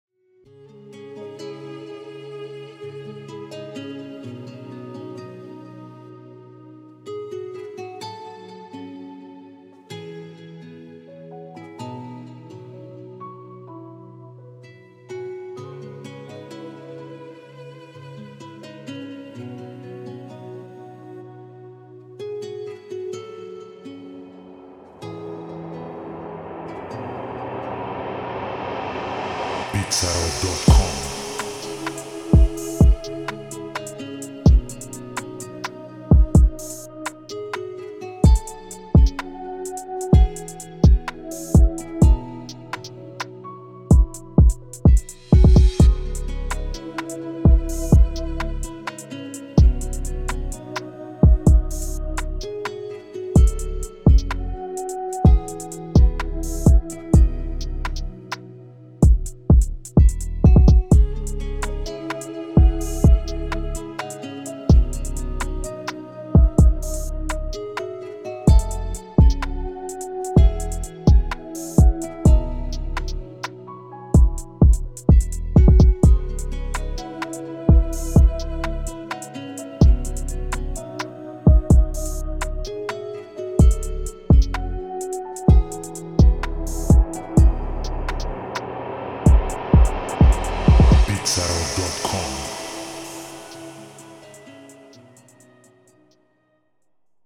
دسته و ژانر: Trap
سبک و استایل: احساسی
سرعت و تمپو: 127 BPM